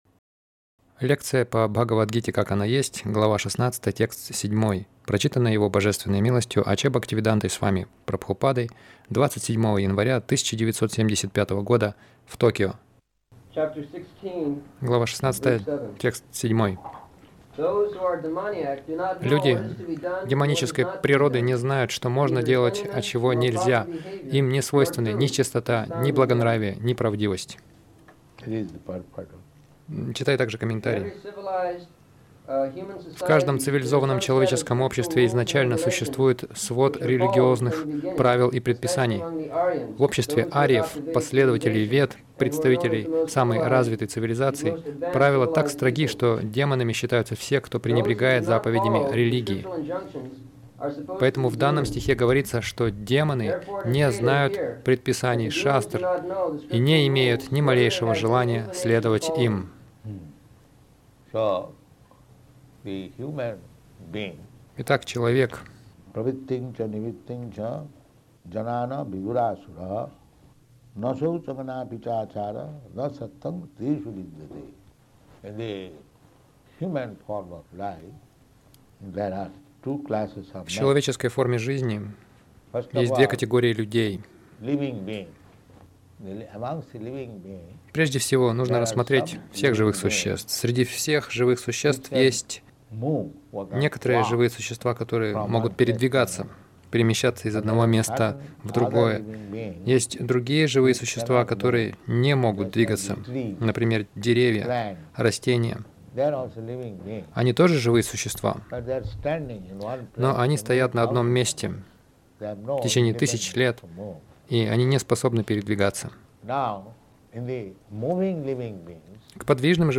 Милость Прабхупады Аудиолекции и книги 27.01.1975 Беседы | Токио Беседа — Есть контролирующий повелитель Загрузка...